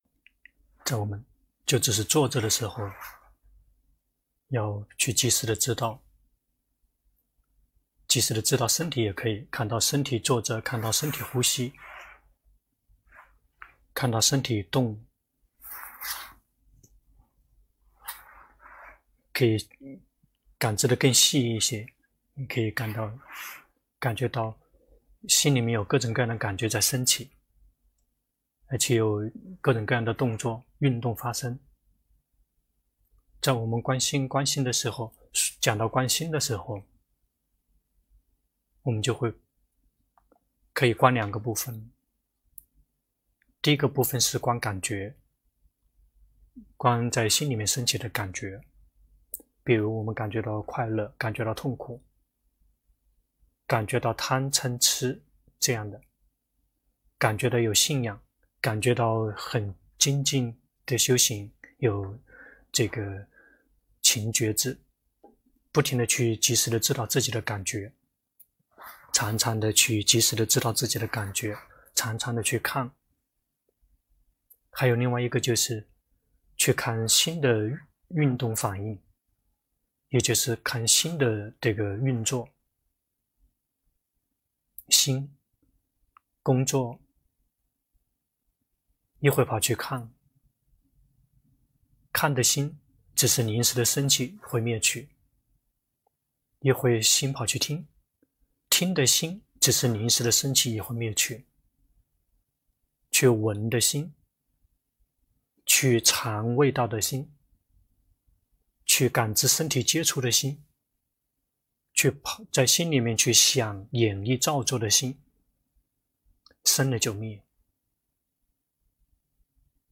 2020年8月23日｜泰國解脫園寺